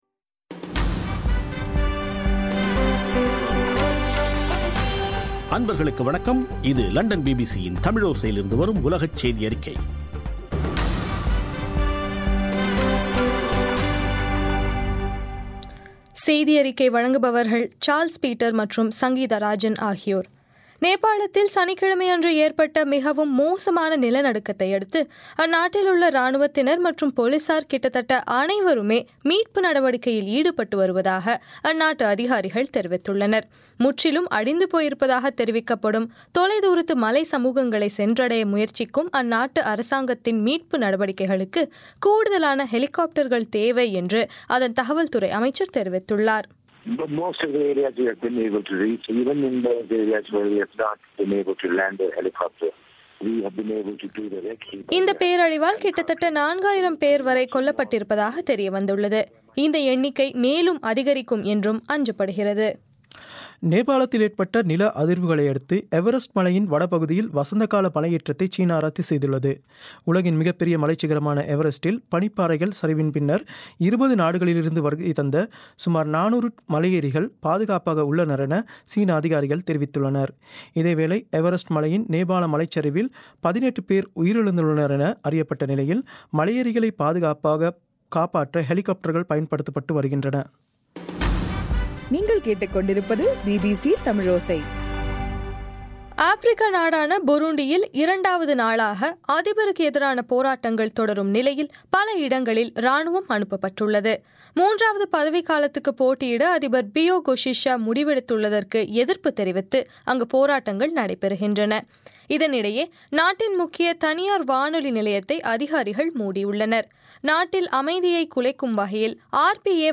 இன்றைய (ஏப்ரல் 27) பிபிசி தமிழோசை செய்தியறிக்கை